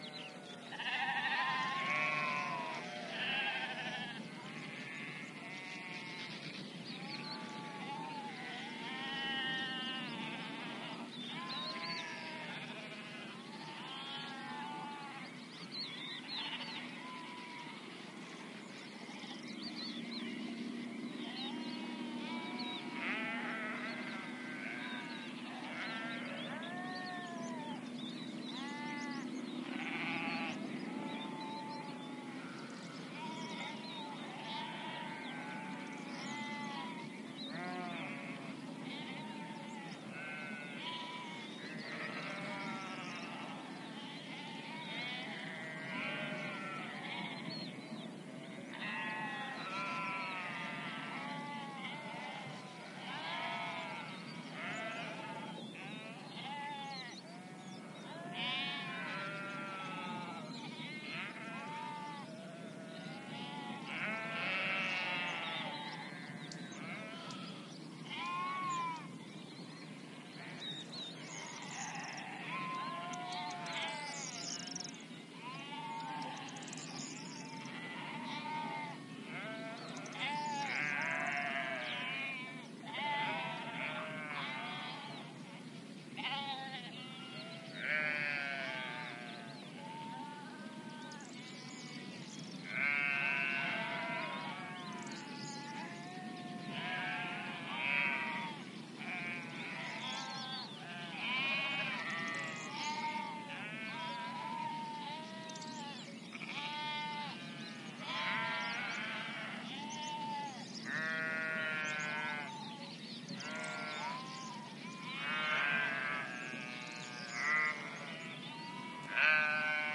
描述：一个人在模仿羊的声音说"咩！"
Tag: 山羊 男性 咩咩咩 羊肉 鸣叫